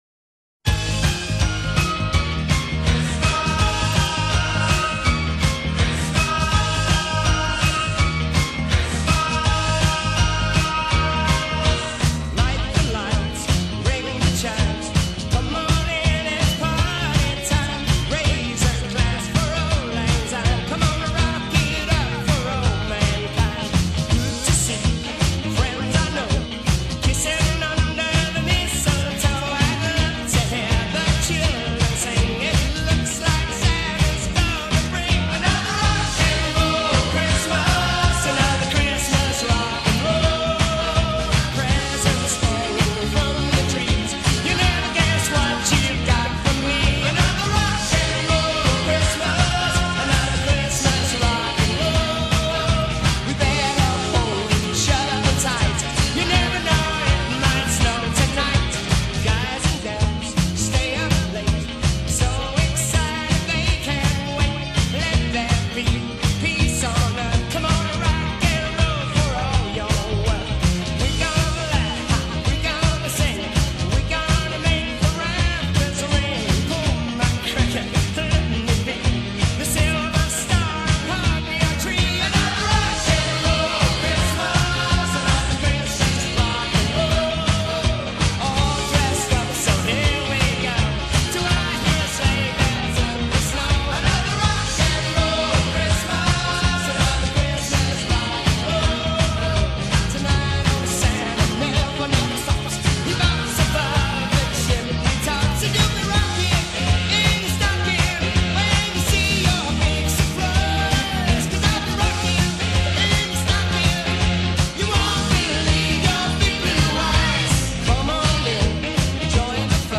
los villancicos más roqueros